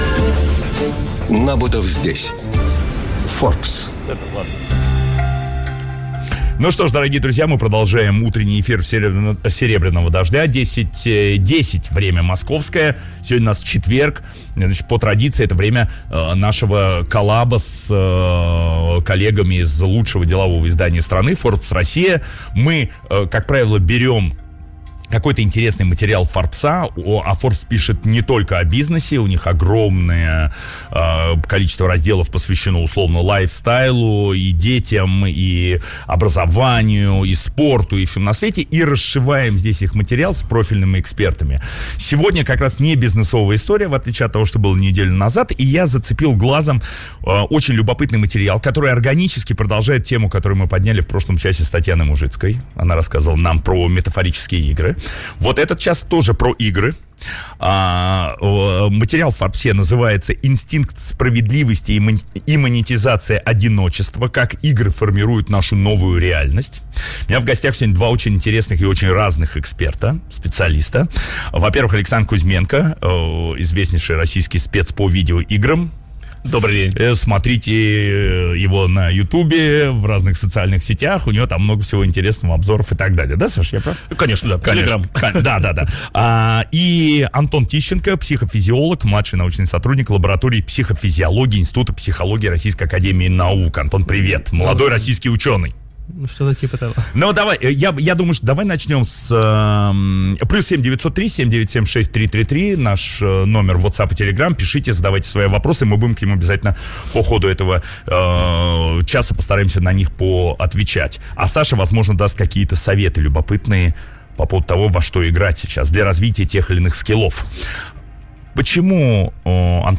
Каждый четверг в утреннем эфире радиостанции «Серебряный дождь» — программа «Набутов здесь. Forbes».